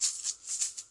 发现 " 摇蛋器 05
描述：Shaker打击乐器自制
Tag: 振动筛 打击乐 国产